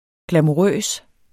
Udtale [ glamuˈʁœˀs ]